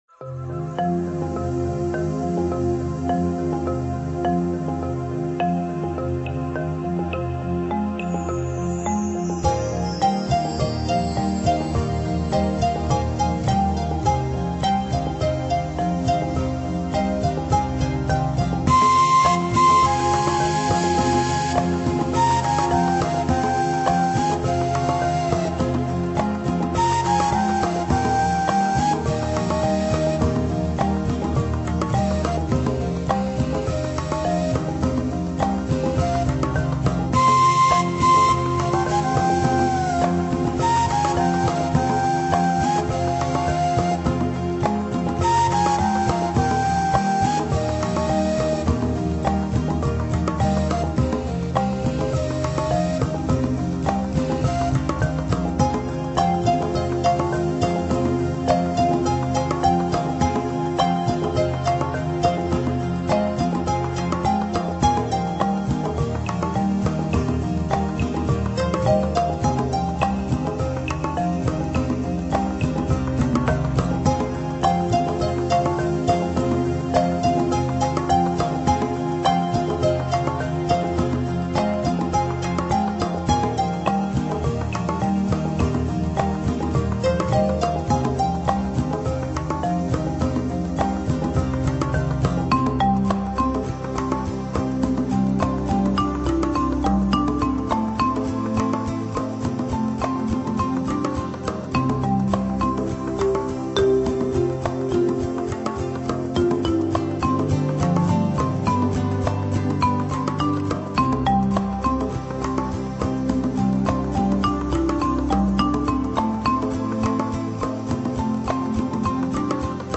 與大自然環境音效，以全方位的擬真空間，帶領我們穿越叢林